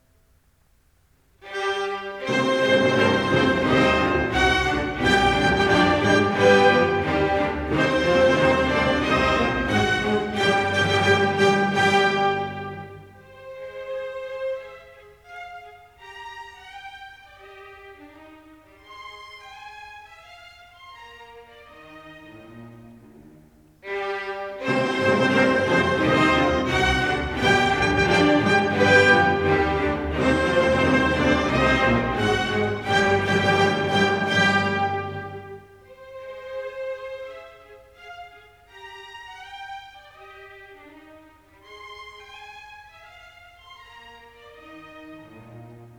Andante pił tosto allegretto